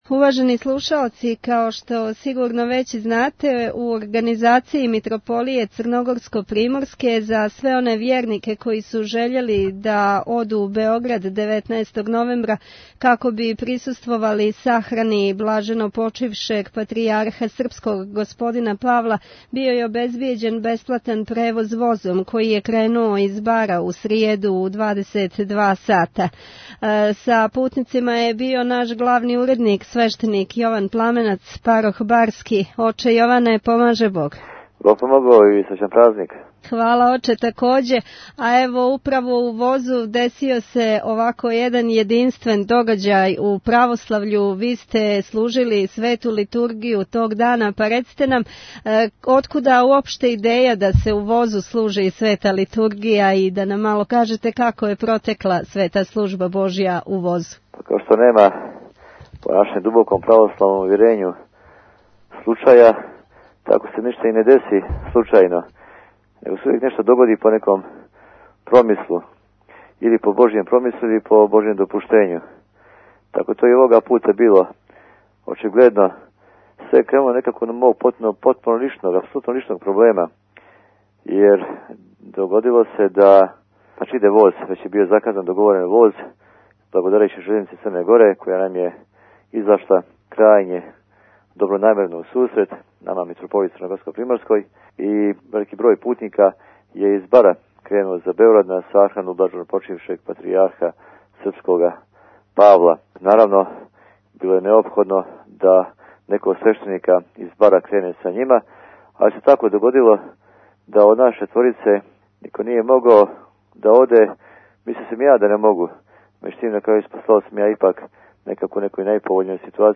Извјештаји